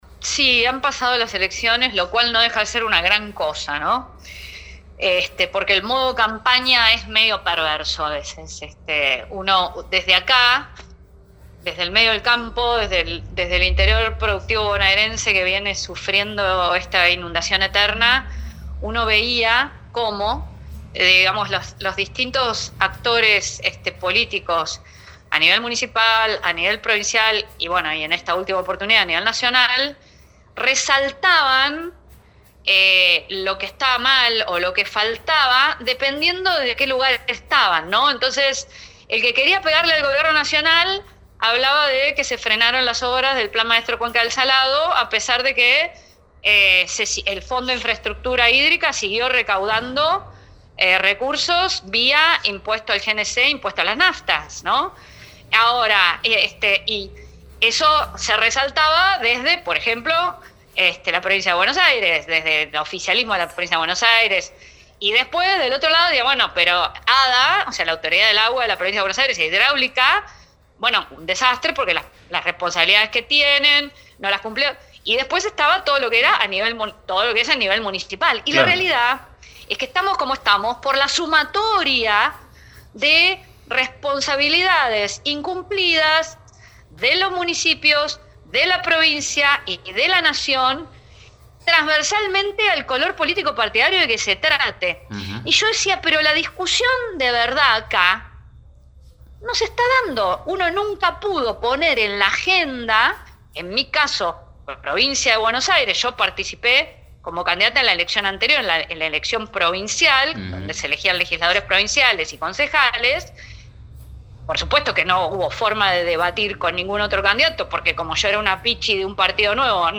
Según analizó en diálogo con El Campo Hoy, la voz del interior productivo se siente marginada en la agenda política.